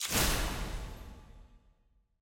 sfx-eog-ui-grandmaster-burst.ogg